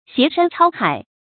挟山超海 xié shān chāo hǎi
挟山超海发音